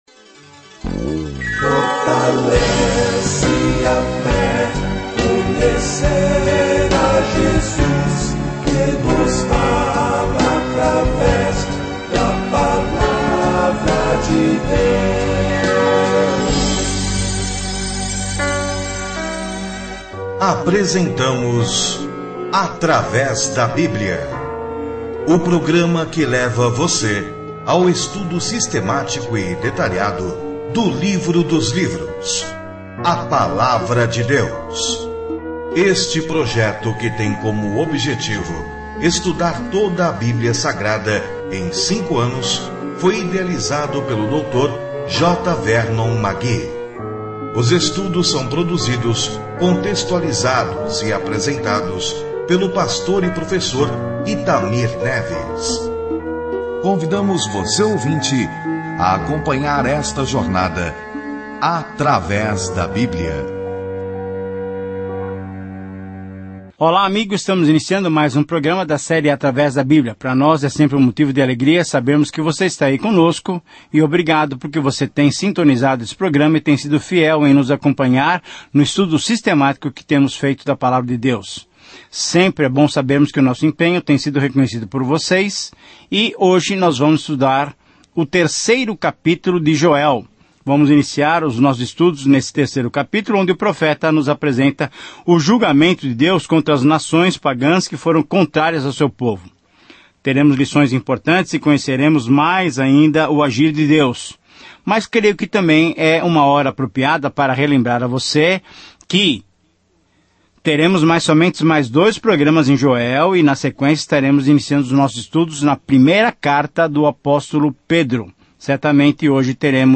As Escrituras Joel 3:1-8 Dia 7 Começar esse Plano Dia 9 Sobre este Plano Deus envia uma praga de gafanhotos para julgar Israel, mas por trás de seu julgamento está uma descrição de um futuro profético “dia do Senhor”, quando Deus finalmente terá uma palavra a dizer. Viaje diariamente por Joel enquanto ouve o estudo em áudio e lê versículos selecionados da palavra de Deus.